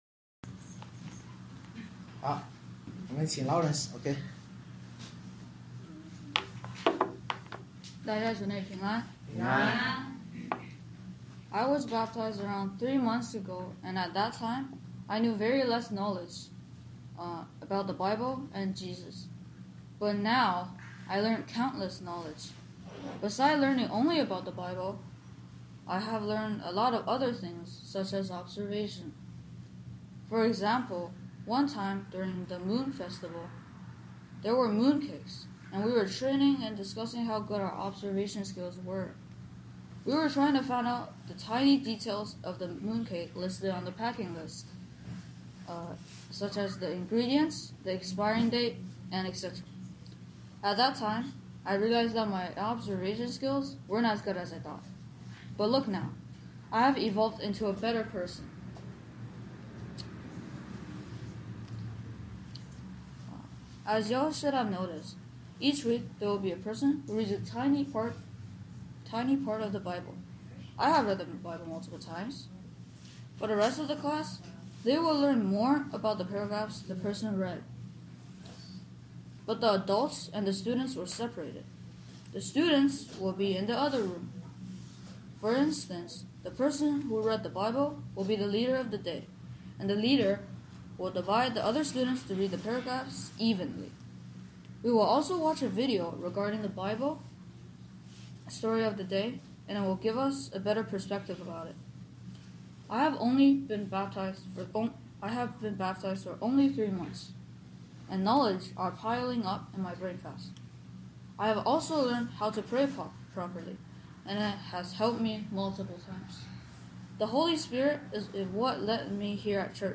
主日见证《敬畏主是智慧的开始》（箴1章1—7节）-online-audio-converter.com_.mp3